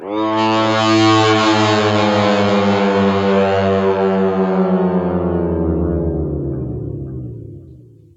gtdTTE67012guitar-A.wav